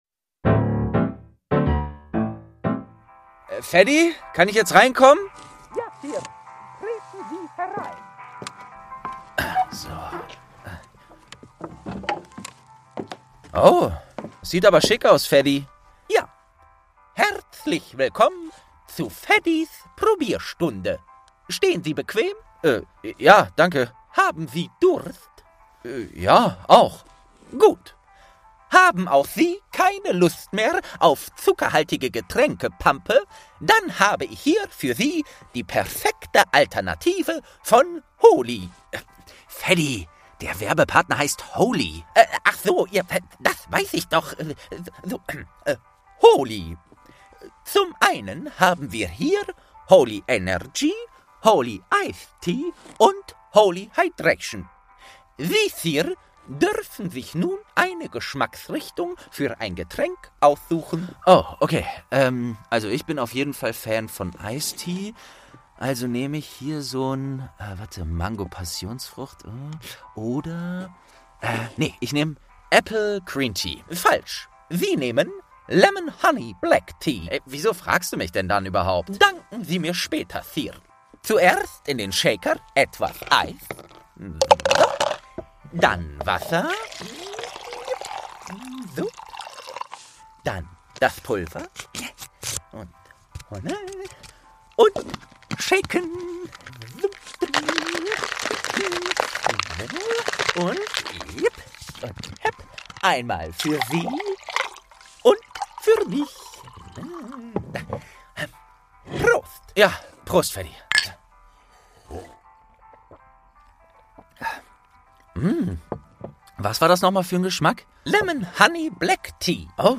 Geschichten aus dem Eberkopf - Ein Harry Potter Hörspiel-Podcast